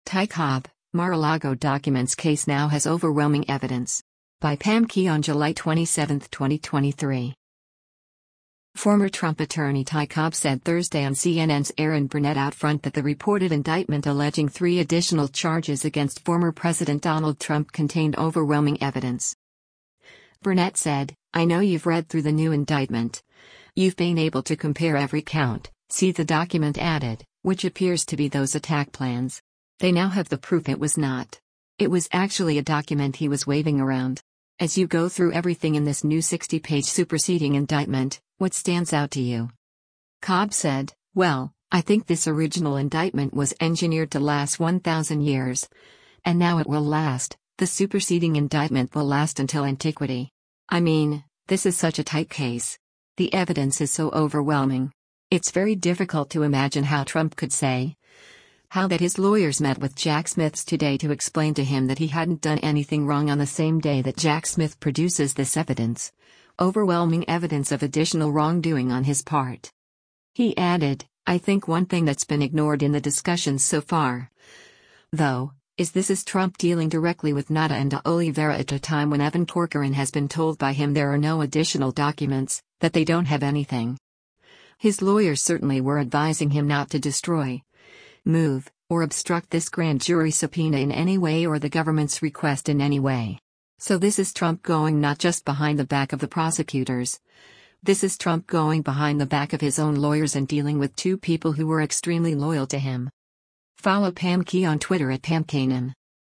Former Trump attorney Ty Cobb said Thursday on CNN’s “Erin Burnett OutFront” that the reported indictment alleging three additional charges against former President Donald Trump contained “overwhelming evidence.”